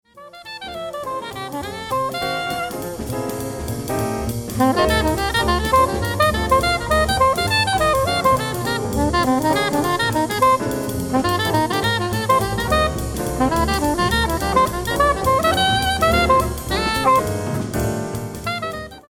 soprano solo